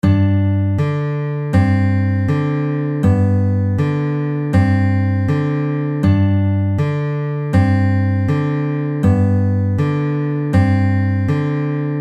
Exercise 5 - Pinching a simple melody
This exercise is a simple “alternate bass with pinch” exercise – yet we change the melody note that we pinch.